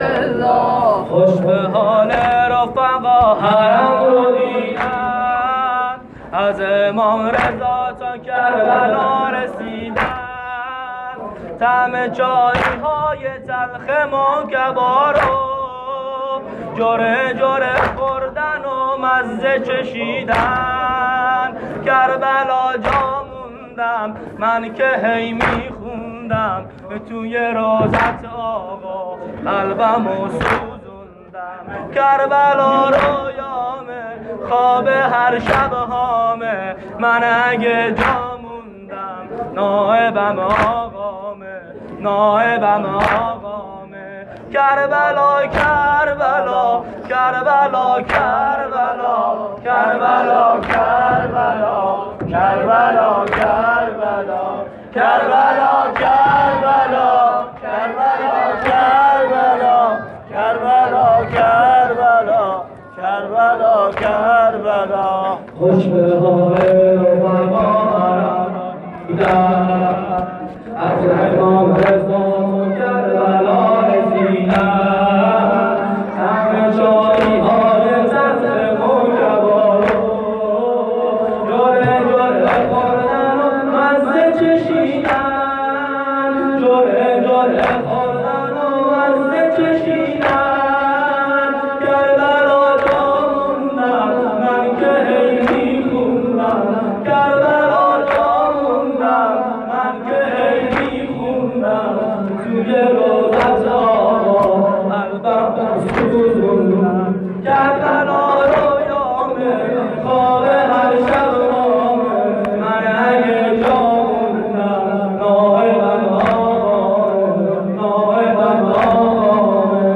جلسه ظهر اربعین حسینی96 هیئت میثاق الحسین {ع}